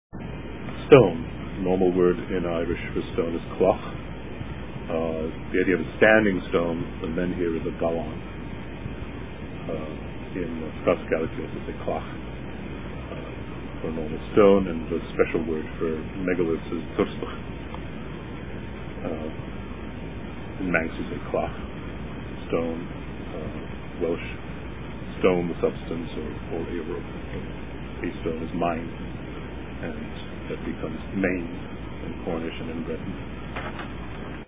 at the Etheracon Festival in January of 2003